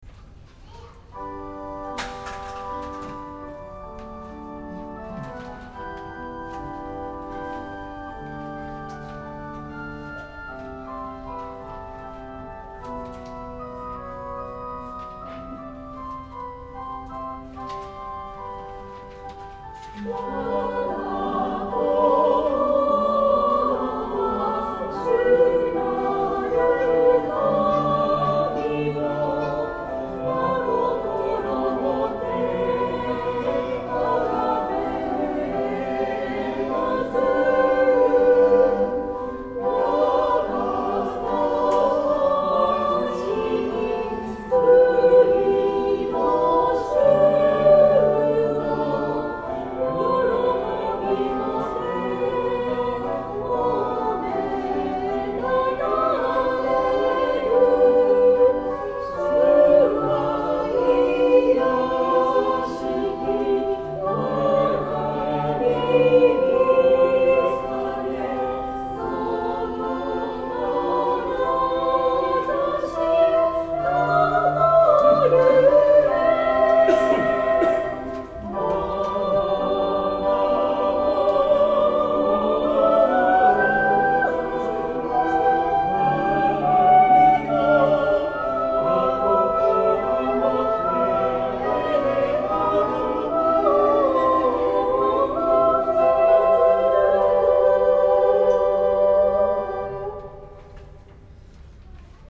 聖歌隊
合唱